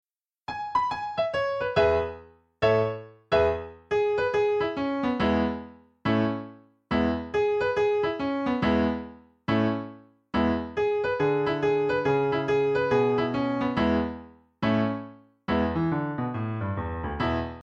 Piano Solo
Downloadable Instrumental Track